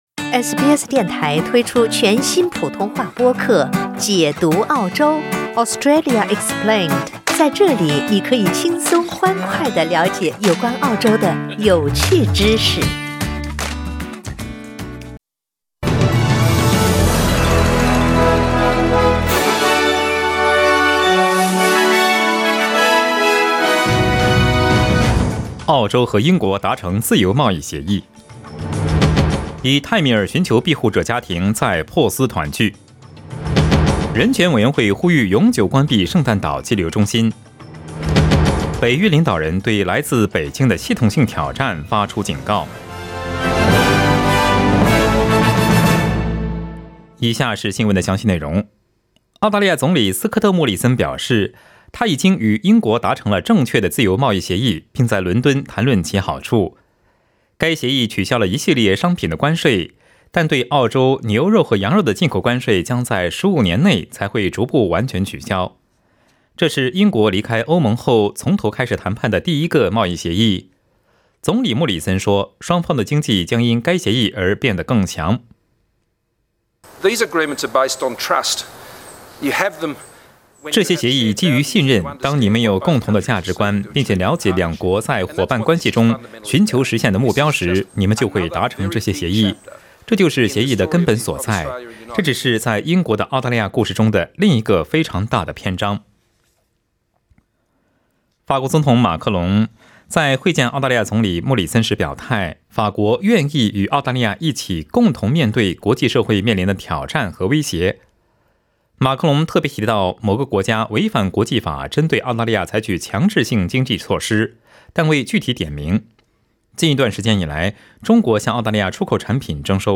SBS早新聞 （6月16日）
SBS Mandarin morning news Source: Getty Images